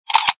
camera.ogg